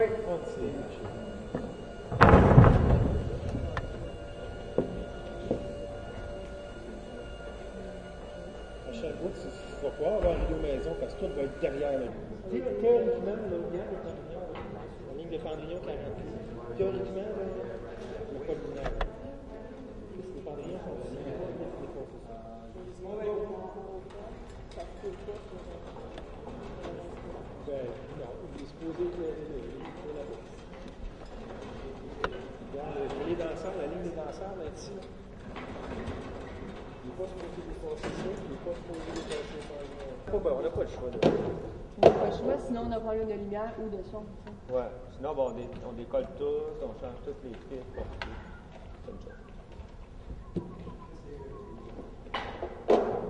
随机 " 剧院工作人员的舞台设置 魁北克语和普通话的声音3